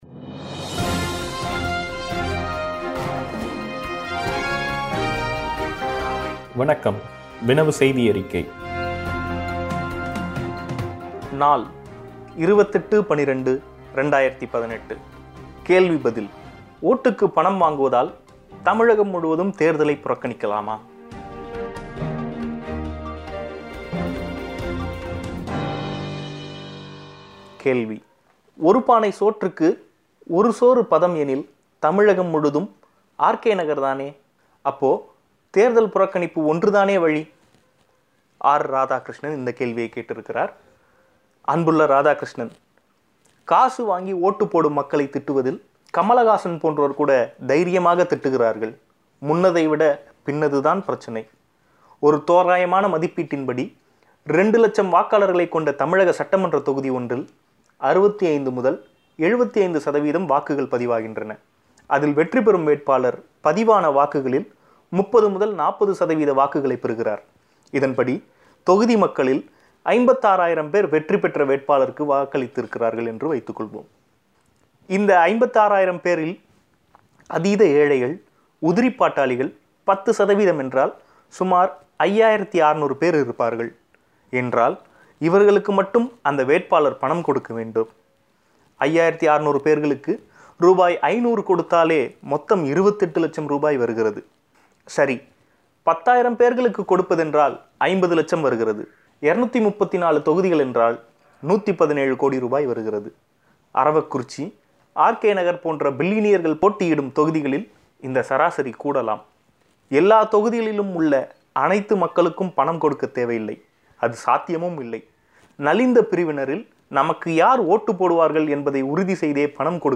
ஓட்டுக்குப் பணம் வாங்குவதால் தமிழகம் முழுவதும் தேர்தலைப் புறக்கணிக்கலாமா ? கேள்வி பதில் ... கஜா புயல் பாதித்த பகுதிகளைத் தாக்கும் நுண்கடன் நிறுவனங்கள் ! ... உள்ளிட்ட கட்டுரைகள் ஒலி வடிவில்.